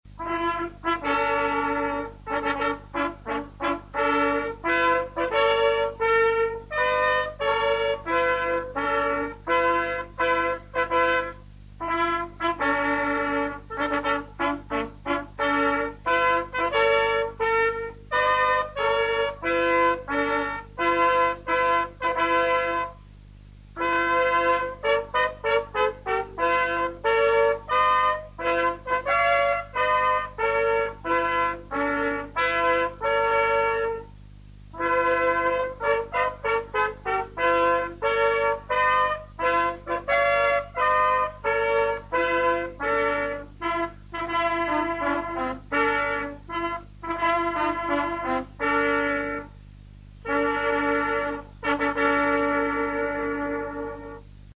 Hier befinden sich die MP3-Dateien, Mitschnitte aus der Radiosendung Aufhorchen in Niederösterreich vom Jänner 2008.
Erste Fanfare